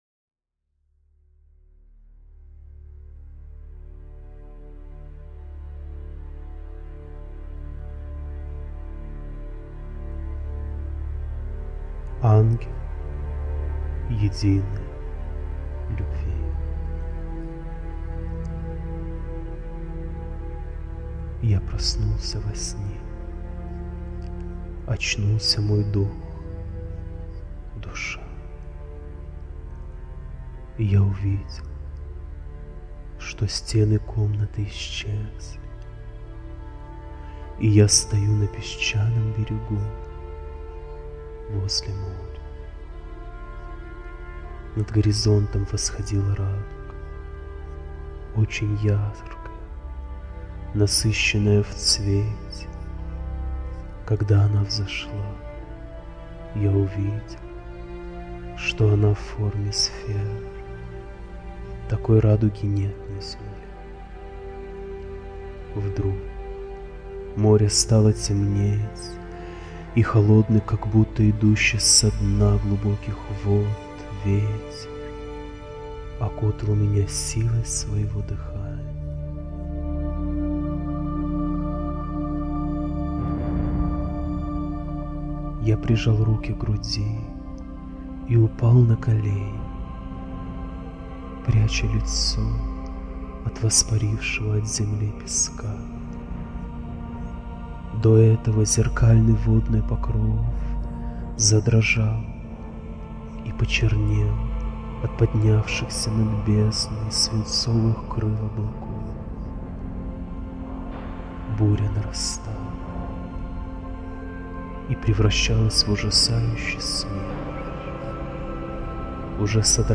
музыка - "300 спартанцев"
авторское прочтение